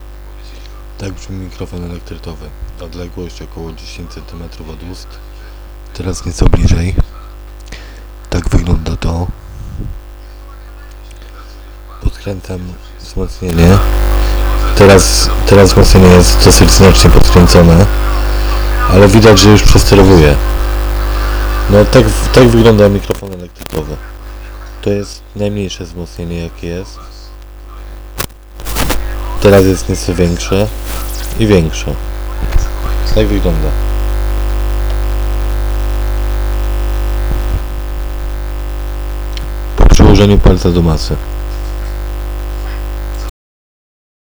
Dźwięk z mikrofonu elektretowego jest bardziej metaliczny i dużo cichszy.
TEST – brzmienie przedwzmacniacza z mikrofonem elektretowym